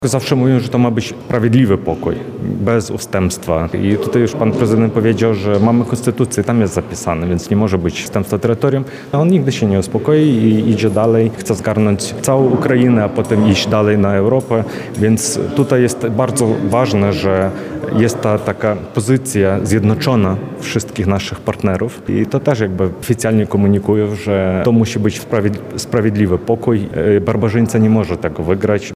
– Mam nadzieję na trwały pokój, ale o żadnym oddaniu terytorium nie może być mowy – tak o ewentualnych rozmowach pokojowych z Rosją mówi konsul generalny Ukrainy w Lublinie Oleh Kuts.